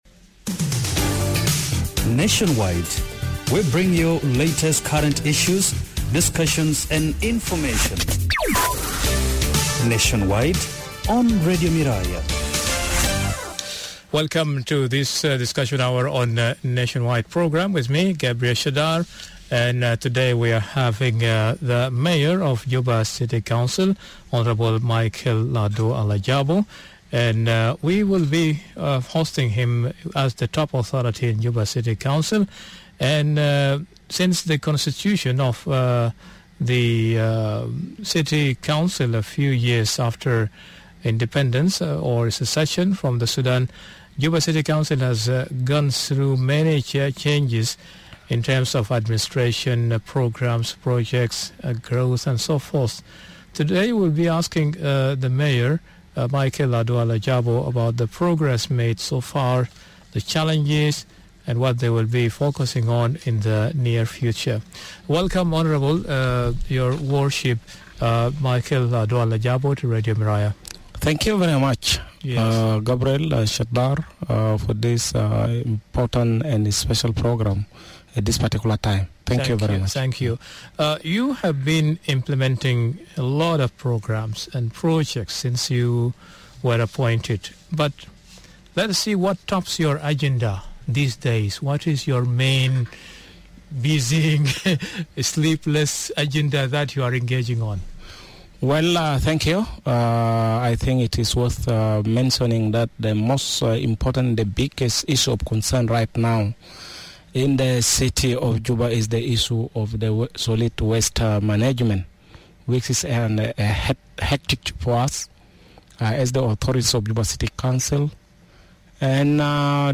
during the Nationwide discussion hour this evening.